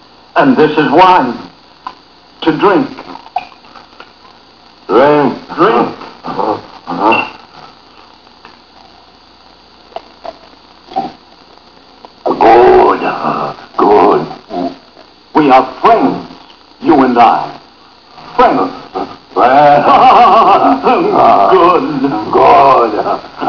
...As Frankenstein's Monster...the Blind man shows him a good time!